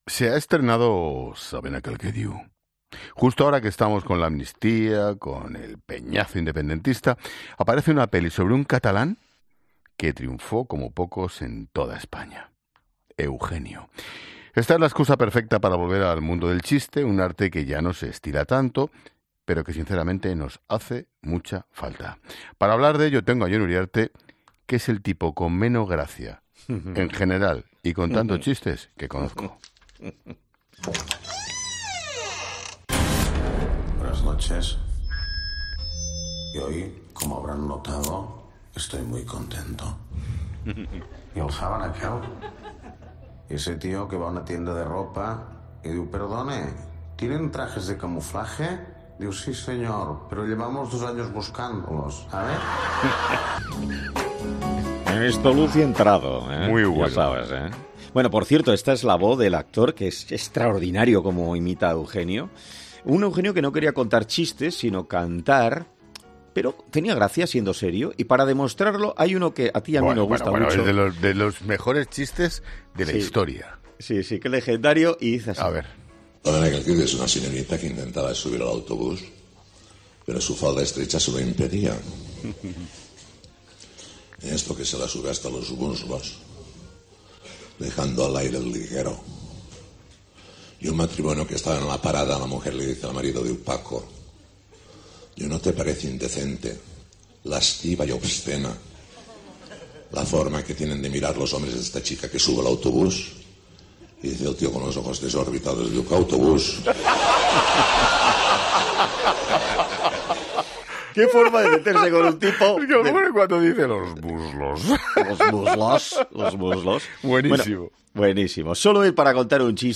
El chiste desataba las risas de los comunicadores y mencionaban que "Luego llaman de los sindicatos y se cabrean, se puede extrapolar a otros funcionarios y no funcionarios, porque aquí vagos de esos conocemos en todas partes".